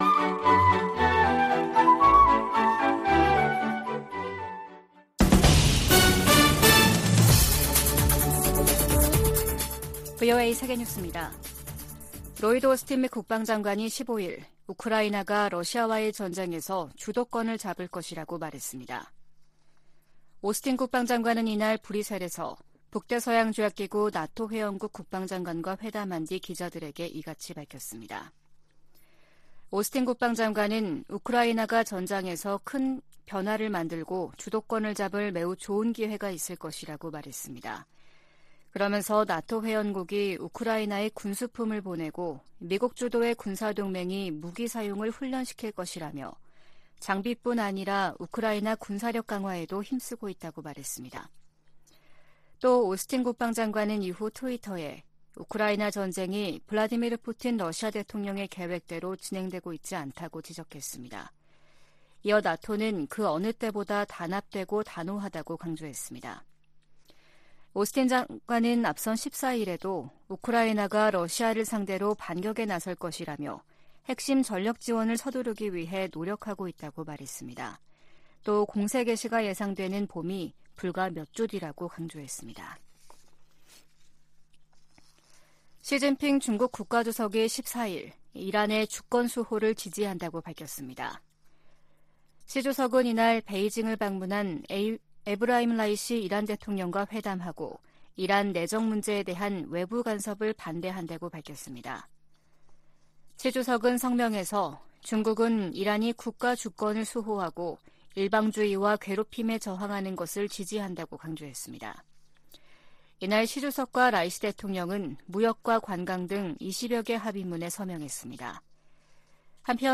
VOA 한국어 아침 뉴스 프로그램 '워싱턴 뉴스 광장' 2023년 2월 16일 방송입니다. 미 국무부가 중국의 정찰풍선 문제를 거론하며, 중국을 미한일 3국의 역내 구상을 위협하는 대상으로 규정했습니다. 백악관은 중국의 정찰풍선 프로그램이 정부의 의도와 지원 아래 운용됐다고 지적했습니다. 북한은 고체연료 ICBM 부대를 창설하는 등, 핵무력 중심 군 편제 개편 움직임을 보이고 있습니다.